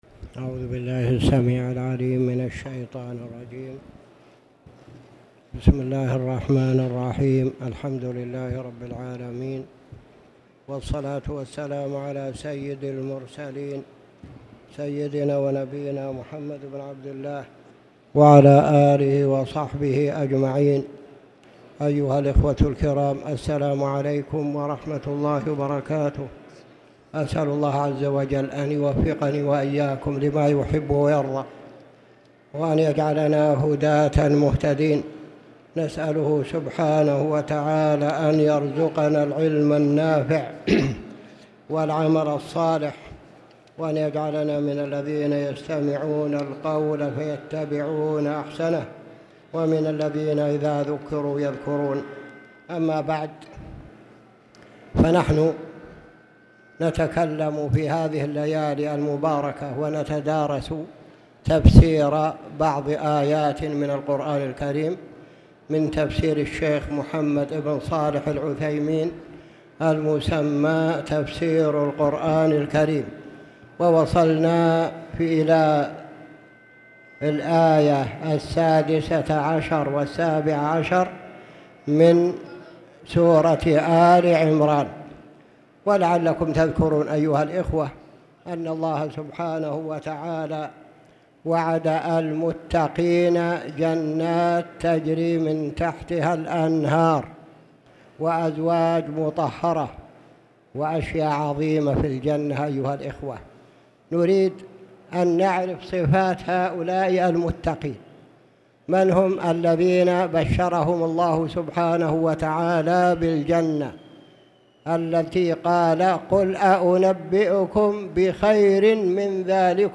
تاريخ النشر ١٩ ربيع الثاني ١٤٣٨ هـ المكان: المسجد الحرام الشيخ